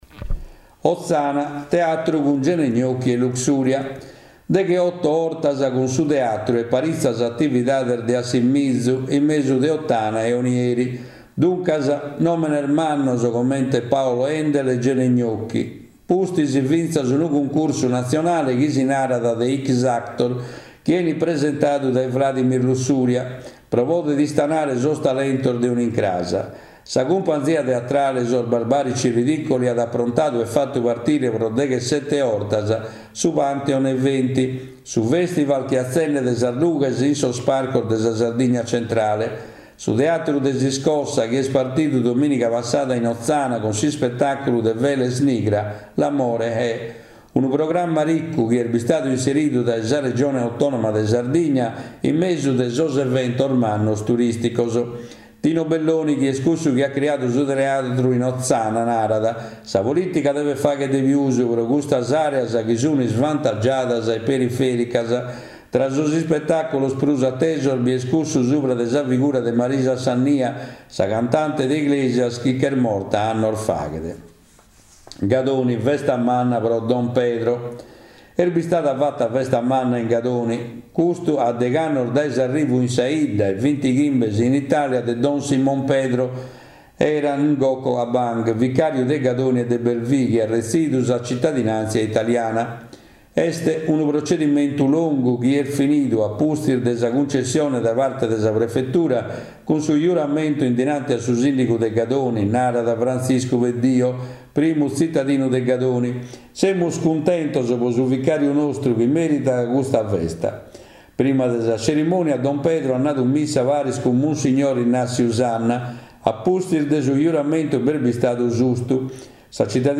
Notiziario in lingua sarda con la consulenza di esperti. Le principali notizie nazionali e nel dettaglio quelle regionali con particolare riferimento all’attività socio economica e culturale della nostra isola con un occhio particolare al mondo dei giovani.